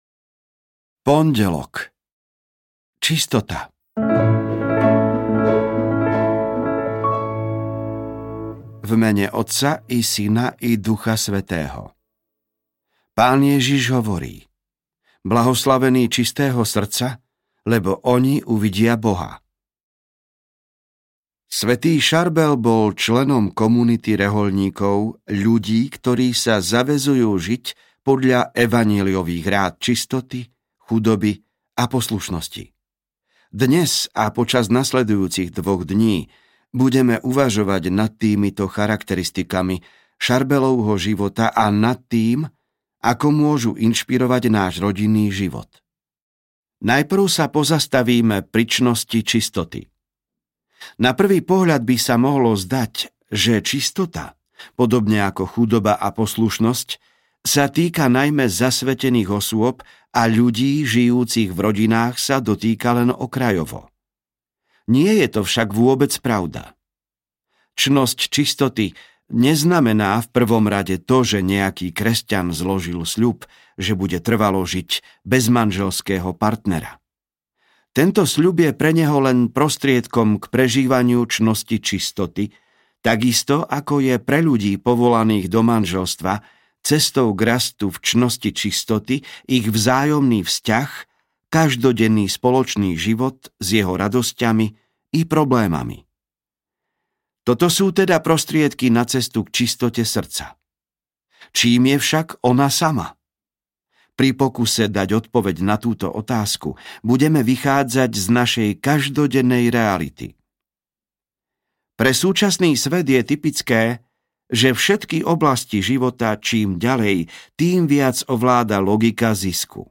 Svätý Charbel, ochraňuj naše rodiny! audiokniha
Ukázka z knihy